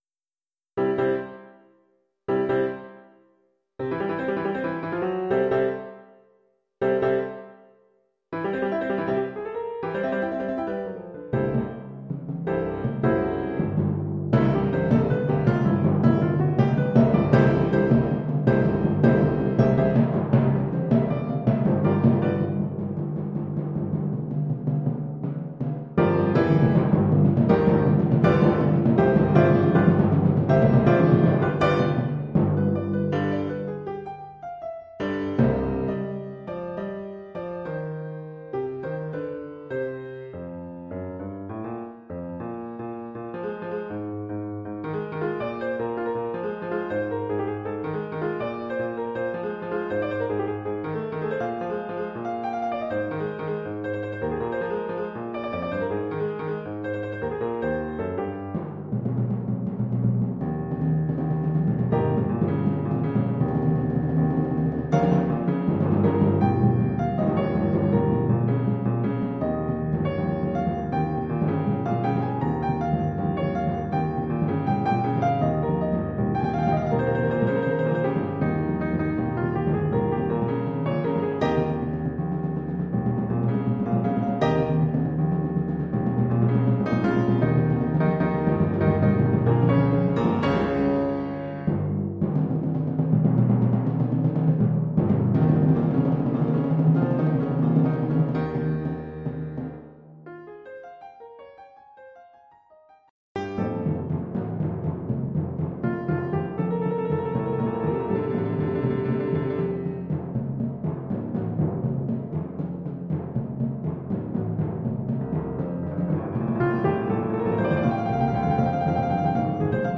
Collection : Percussions
Oeuvre pour 4 timbales et piano.